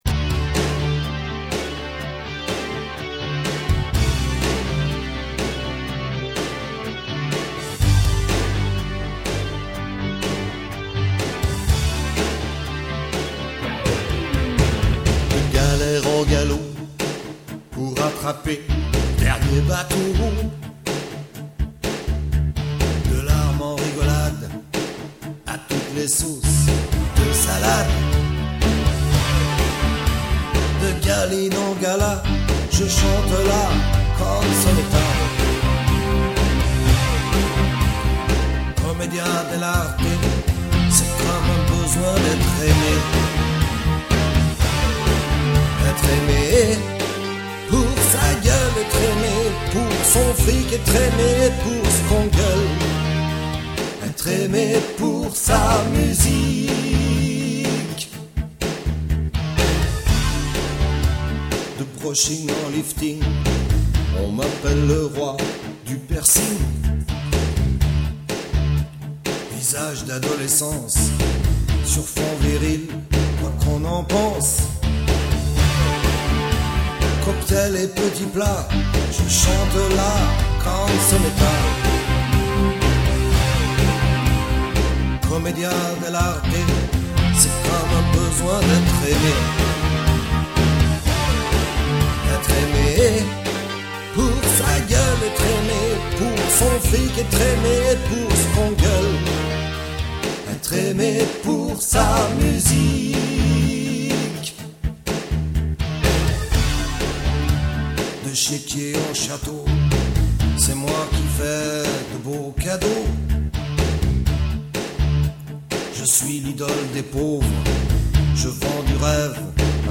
Une idée cadeau pour les musiciens et les non musiciens amoureux de guitare rock !